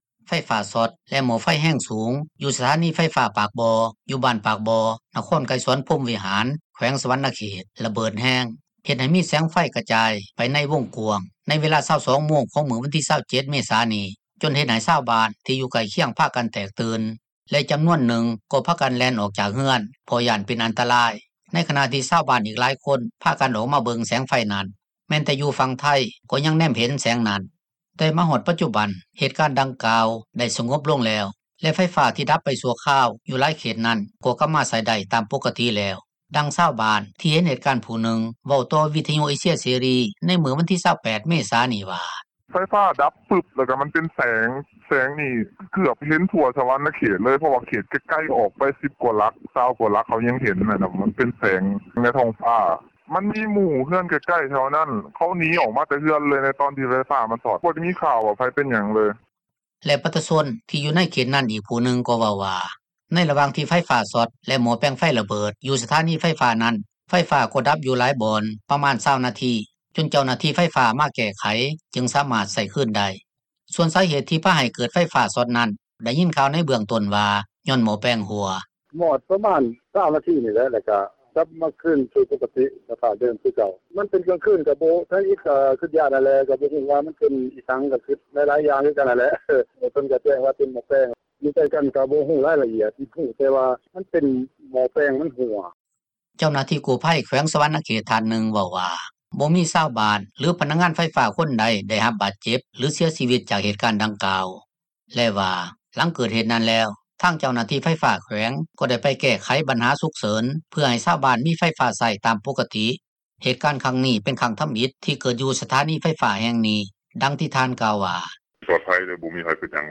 ດັ່ງຊາວບ້ານ ທີ່ເຫັນເຫດການຜູ້ນຶ່ງ ເວົ້າຕໍ່ວິທຍຸເອເຊັຽ ເສຣີ ໃນມື້ວັນທີ 28 ເມສານີ້ວ່າ: